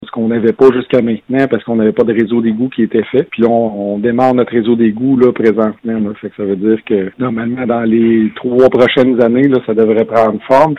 Ce qui devrait prendre forme au cours des prochaines années, comme l’indique le maire Marc-André Gosselin.